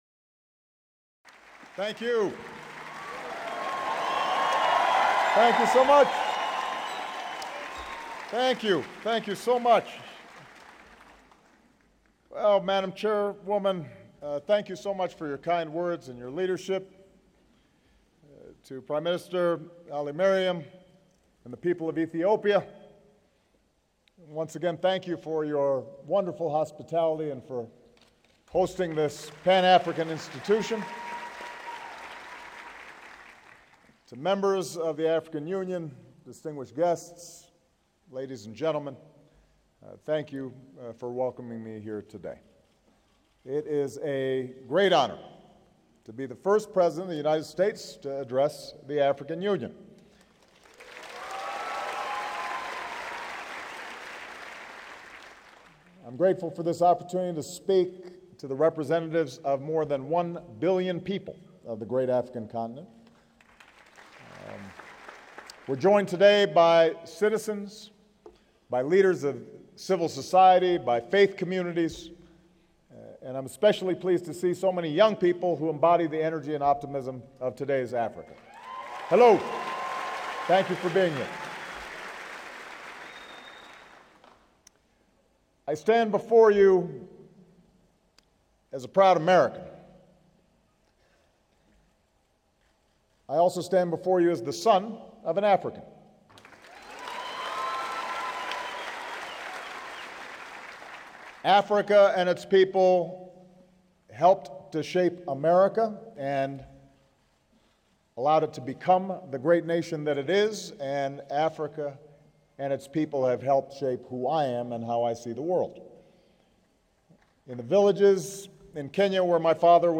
U.S. President Barack Obama speaks at a meeting of the African Union in Addis Ababa, Ethiopia